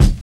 HAT CRUNCHER.wav